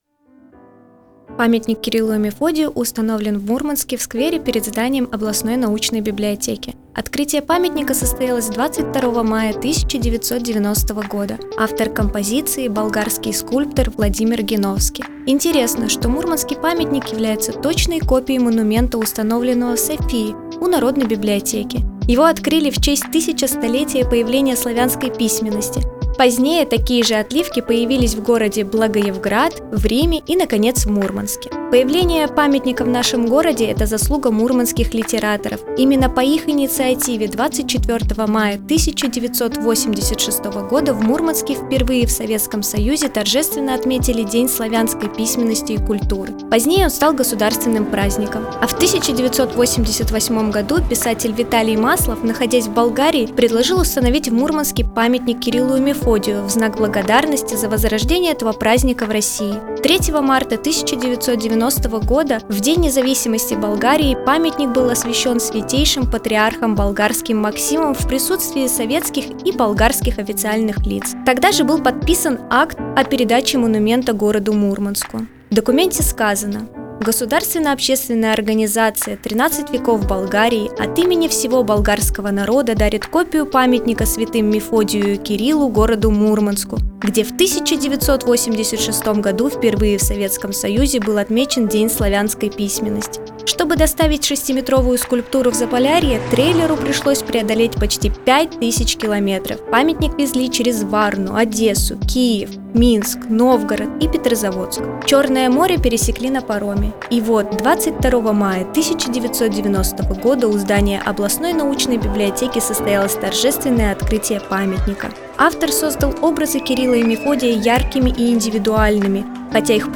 Новая аудиоэкскурсия проекта «51 история города М»